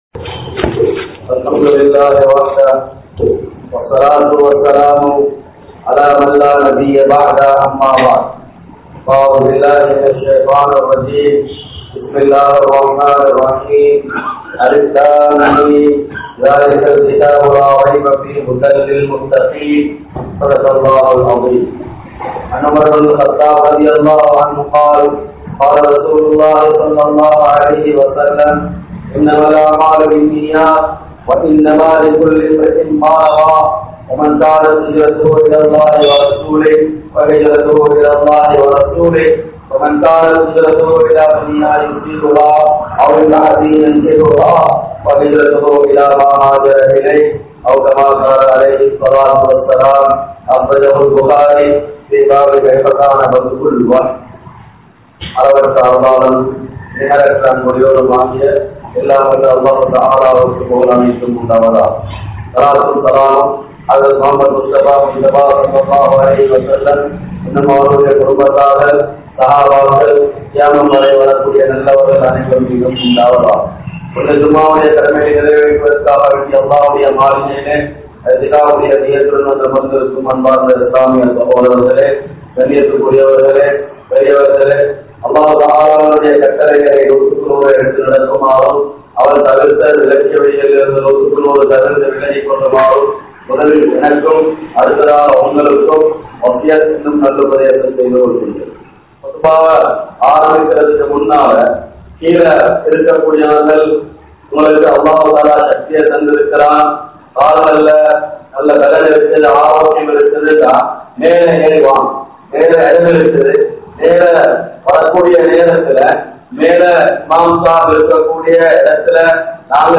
Qualities of Trustees | Audio Bayans | All Ceylon Muslim Youth Community | Addalaichenai
Jamiul Falah Jumua Masjidh